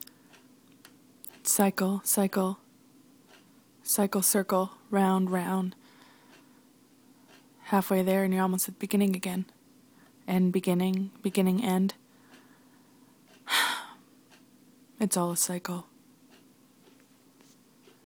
Oh and here’s another dramatic reading.